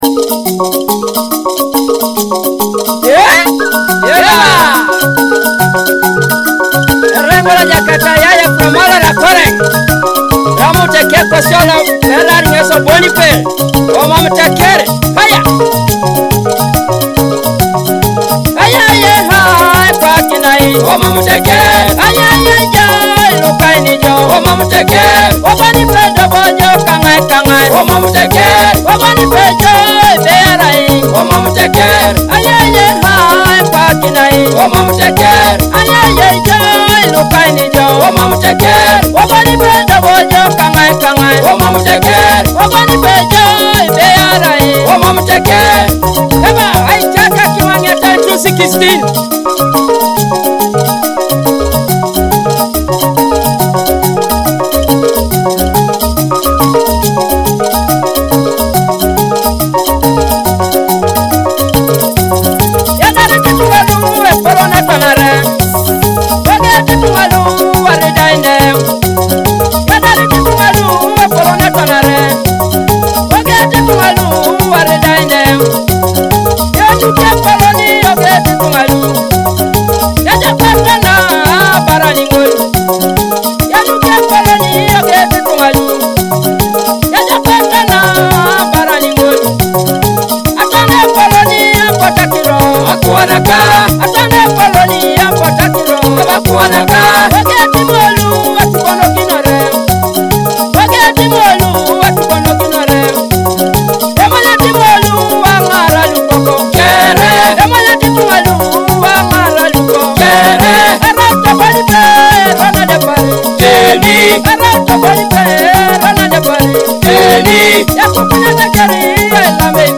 traditional songs